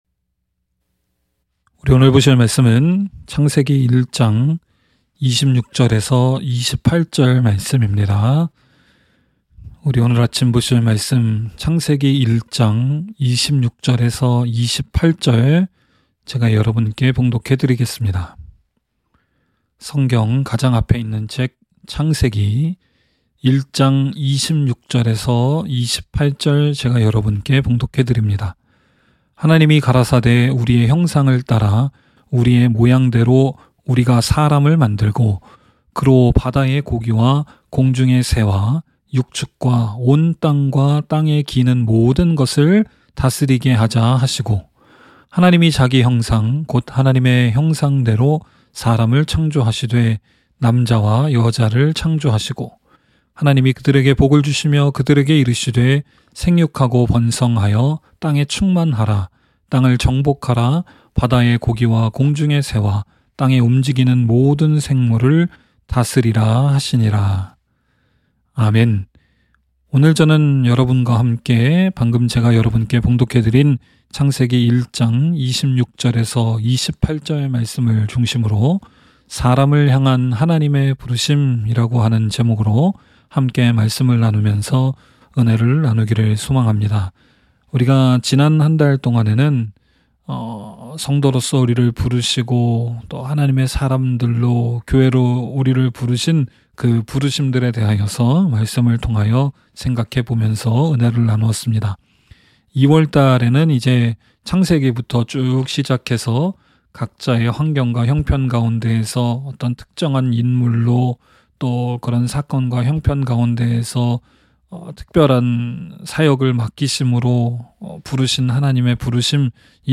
by admin-new | Feb 7, 2022 | 설교 | 0 comments